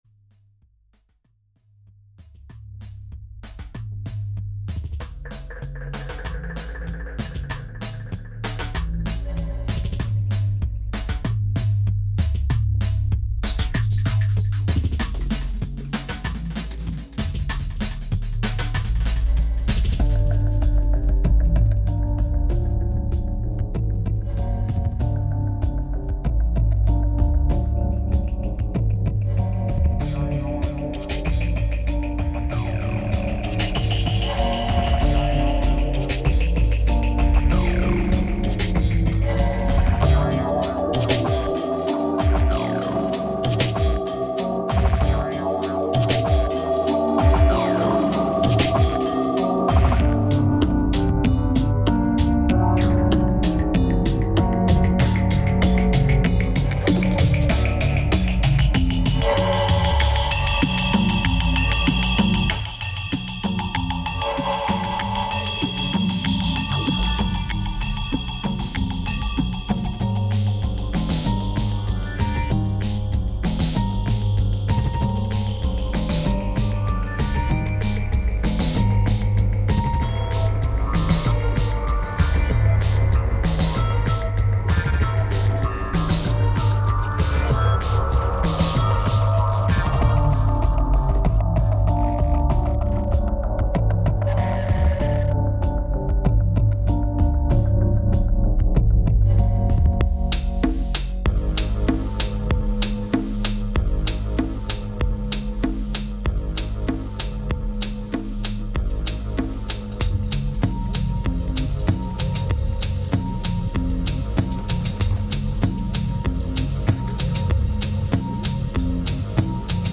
2294📈 - -17%🤔 - 96BPM🔊 - 2009-03-08📅 - -453🌟